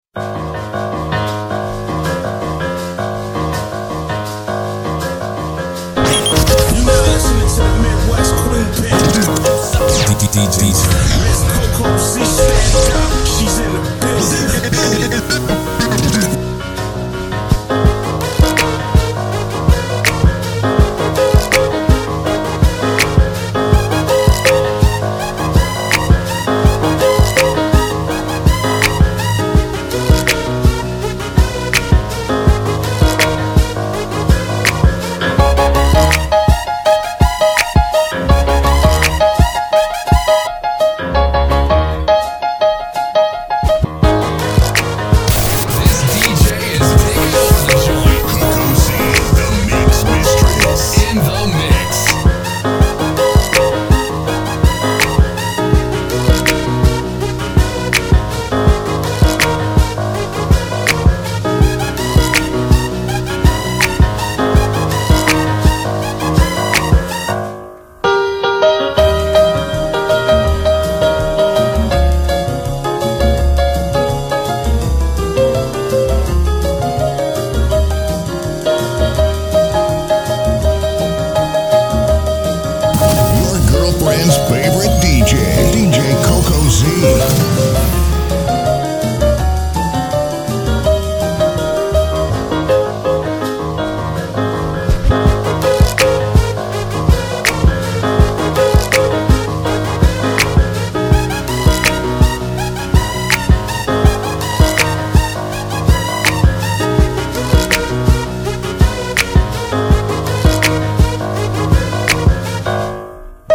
MASH UP!!